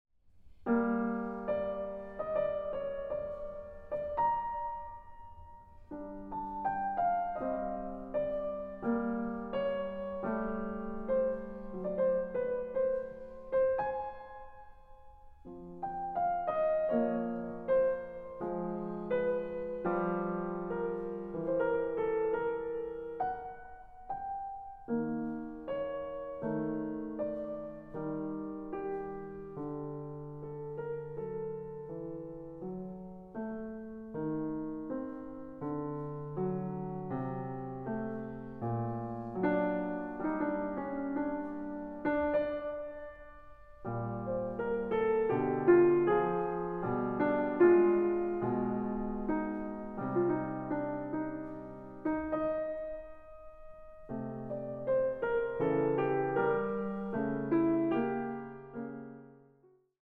Pianistin